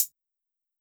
Closed Hats
Hi-Hat (Headlines).wav